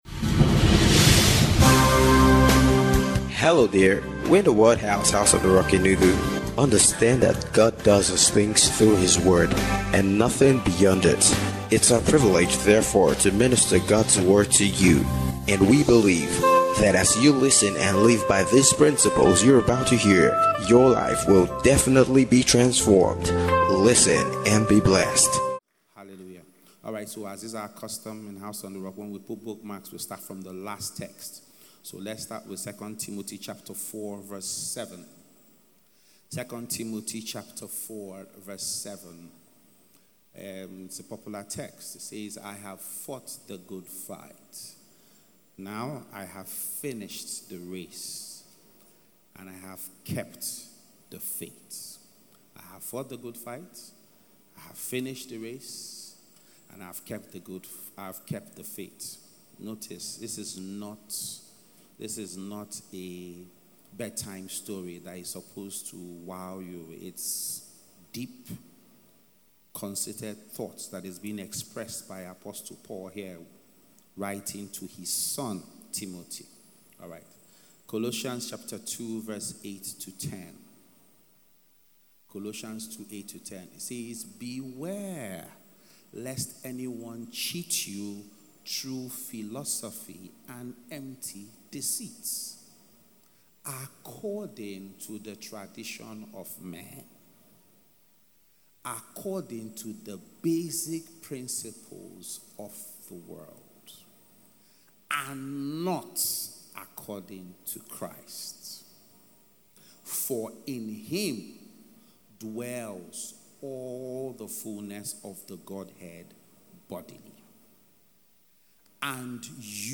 FINISHING STRONG - FRESH DEW SERVICE